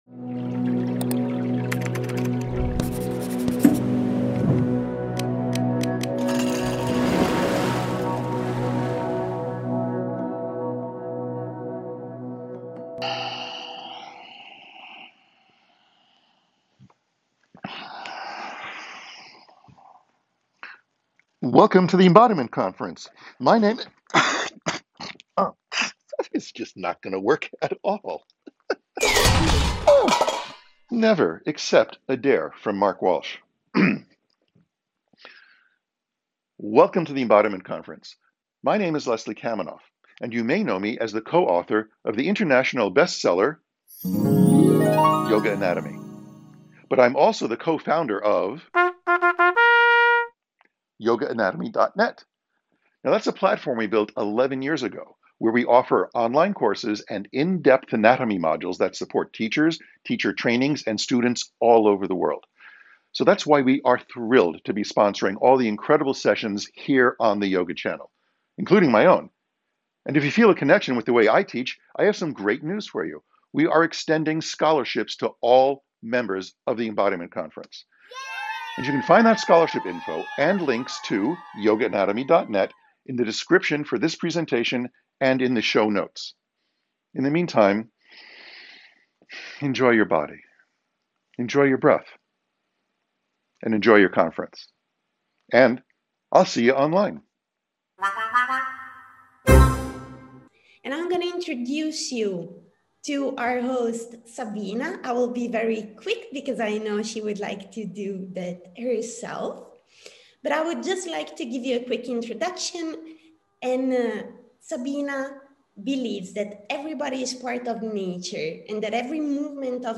Guided Practices